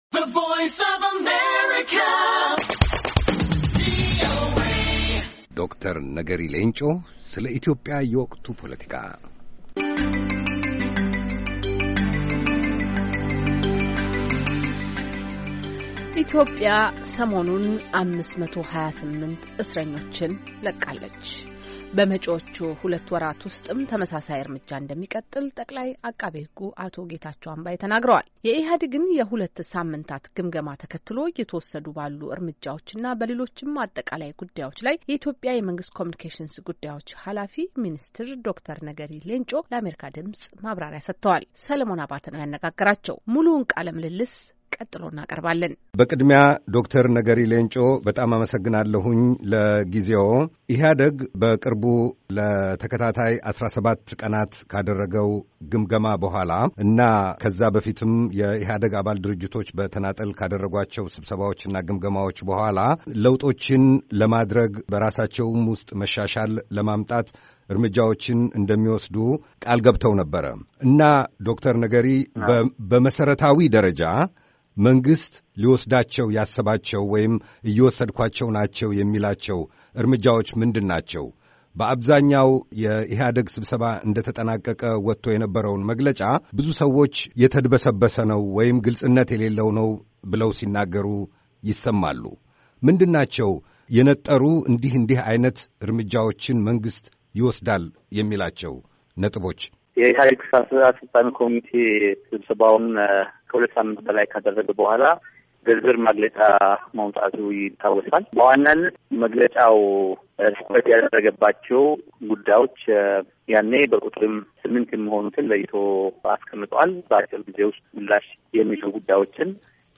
የኢሕአዴግን የሁለት ሣምንታት ግምገማ ተከትሎ እየተወሰዱ ባሉ እርምጃዎችና በሌሎችም አጠቃላይ ጉዳዮች ላይ የኢትዮጵያ የመንግሥት ኮምዩኒኬሽንስ ጉዳዮች ኃላፊ ሚኒስትር ዶ/ር ነገሪ ሌንጮ ለአሜሪካ ድምፅ ማብራሪያ ሰጥተዋል።